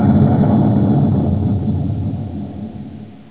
1 channel
cannon.mp3